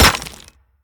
box_marble_open-2.ogg